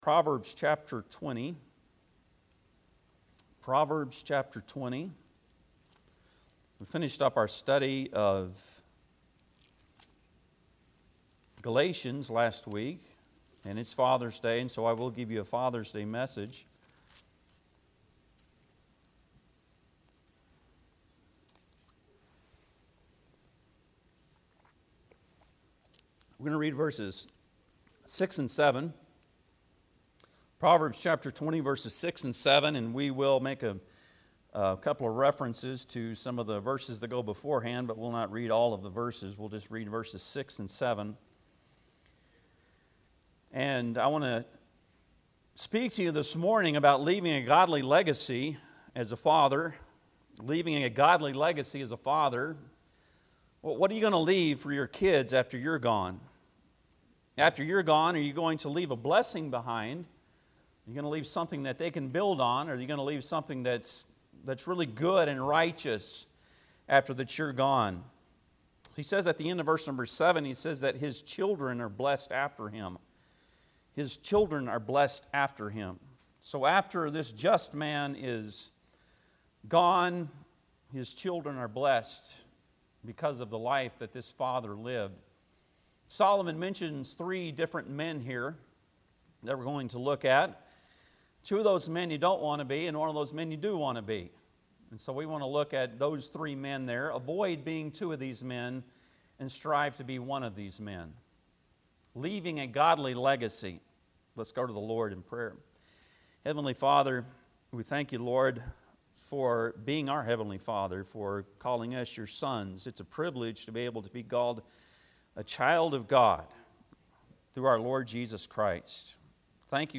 Proverbs 30:6-7 Service Type: Sunday am Bible Text